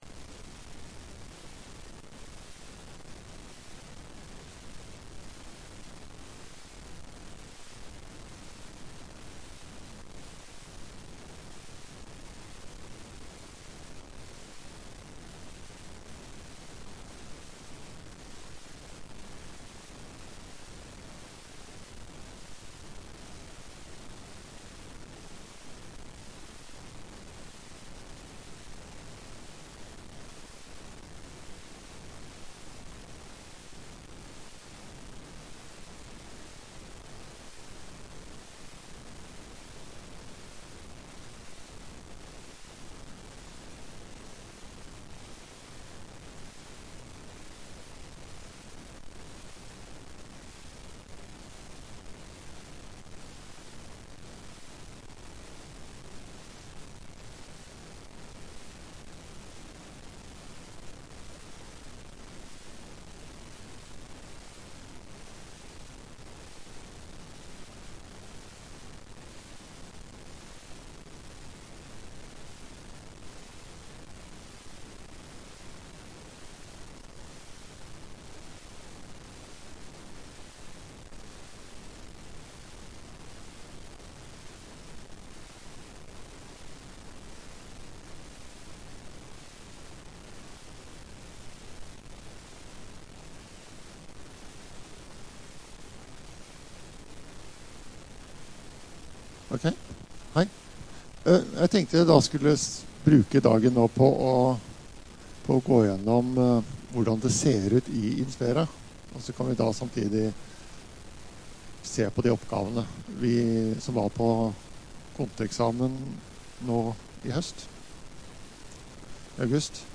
Kjemi plenumsregning 4 (inspera)
Rom: Store Eureka, 2/3 Eureka